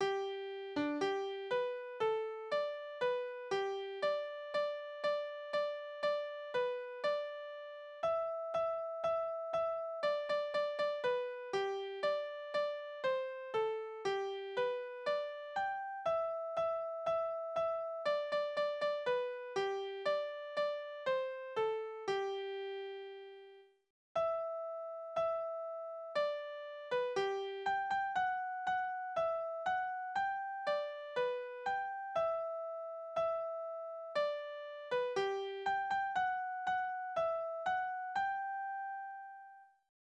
Reservistenlieder: Garnison
Tonart: G-Dur
Taktart: 4/4
Tonumfang: Oktave, Quarte
Besetzung: vokal